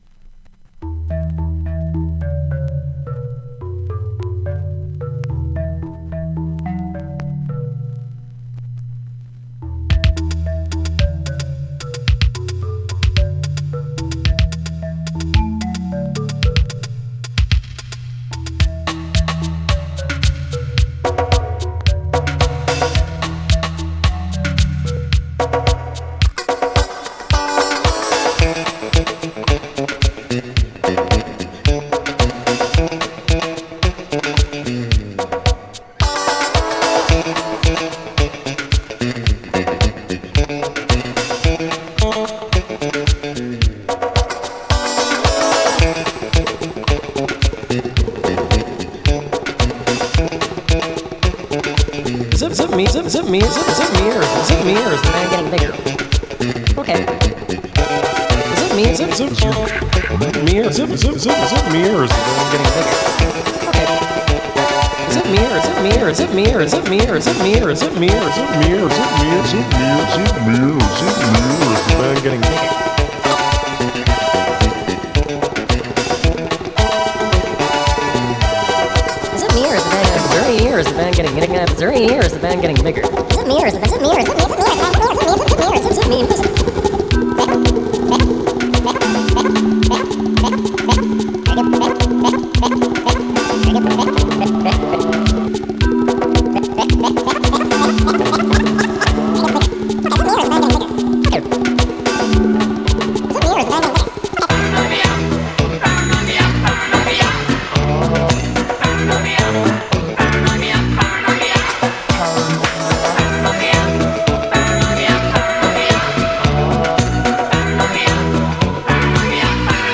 Extended Mix